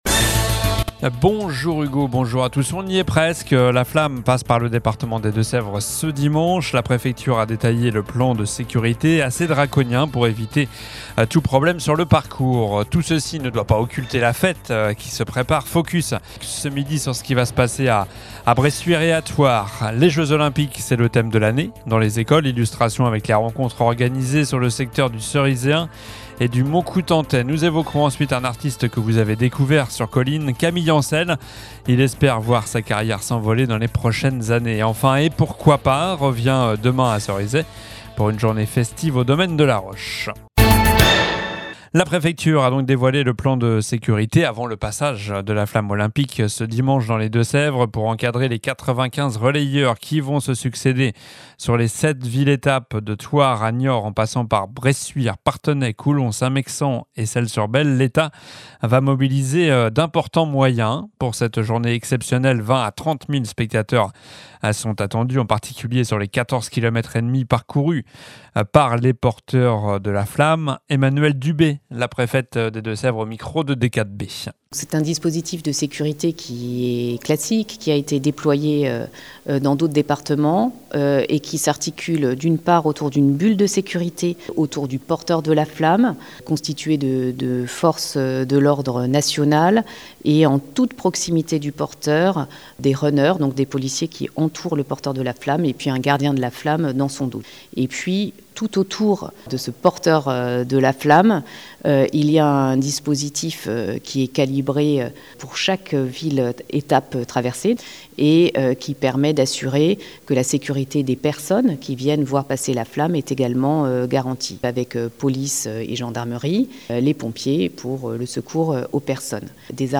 Journal du vendredi 31 mai (midi)